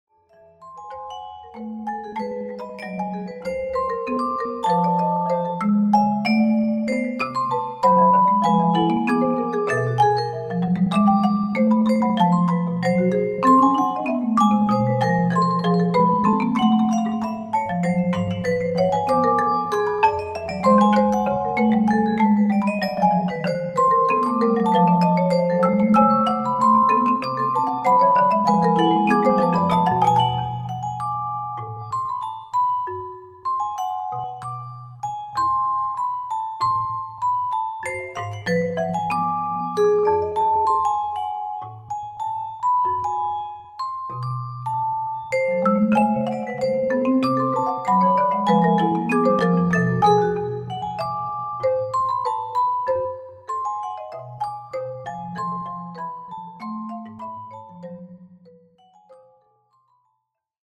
Genre: Percussion Trio
# of Players: 3